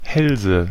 Ääntäminen
Ääntäminen Tuntematon aksentti: IPA: /ˈhɛlzə/ Haettu sana löytyi näillä lähdekielillä: saksa Käännöksiä ei löytynyt valitulle kohdekielelle. Hälse on sanan Hals monikko.